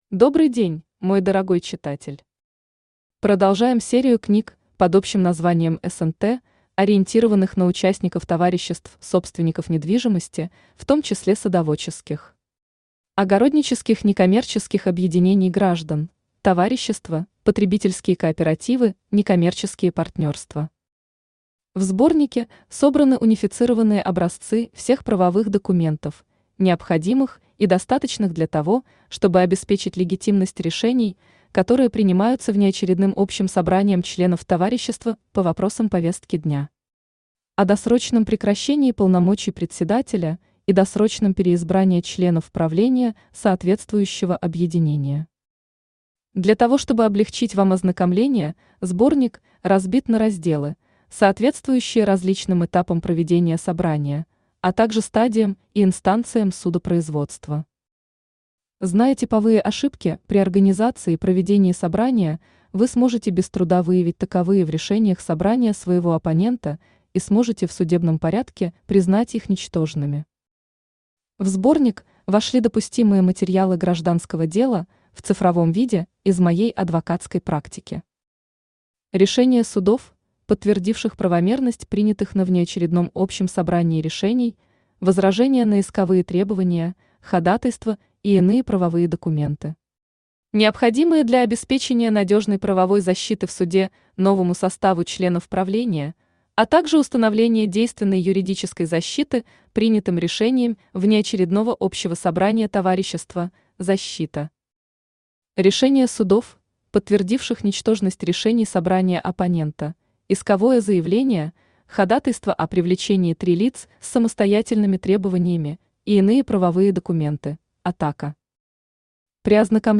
Образцы документов Автор Роман Колганов Читает аудиокнигу Авточтец ЛитРес.